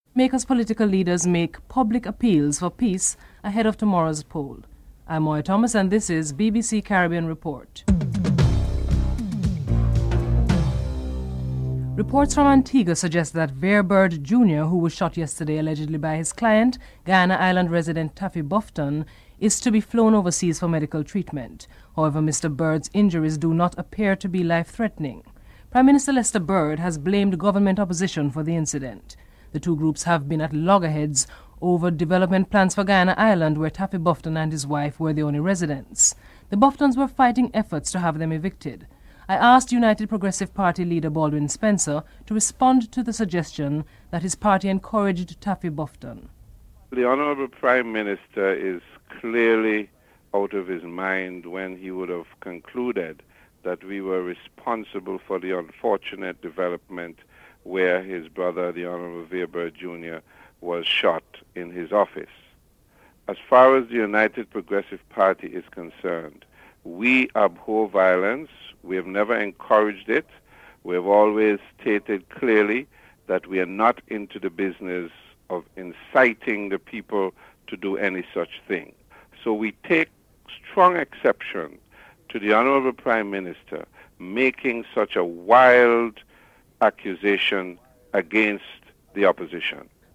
1. Headlines (00:00-00:12)
Baldwin Spencer, Leader of the United Progressive Party is interviewed (00:13-03:27)
Republican Lawmaker John Baker and President Clinton's Special Coordinator on Haiti David Greenlee are interviewed (13:26-15:07)